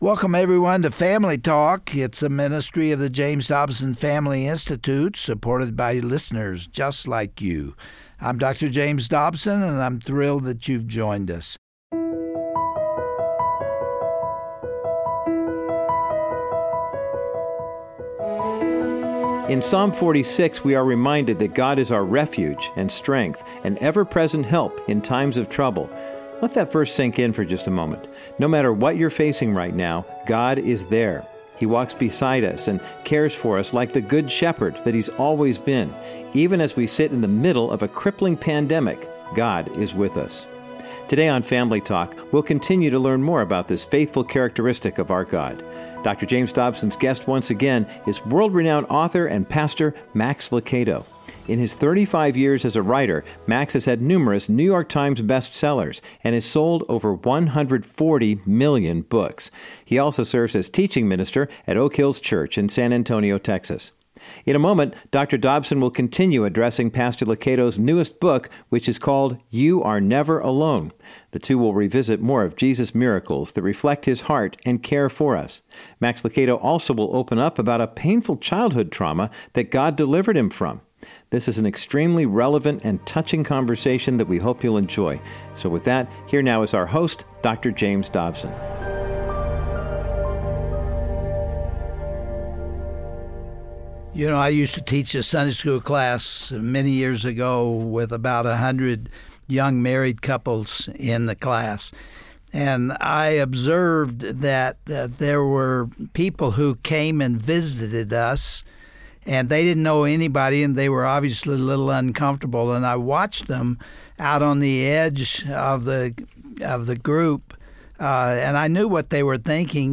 Dr. James Dobson enjoys an enlightening conversation with best-selling author and pastor Max Lucado as they look to the miracles of Jesus that reveal His care and concern for us. Pastor Lucado also recalls a severe childhood trauma in which God radically healed him.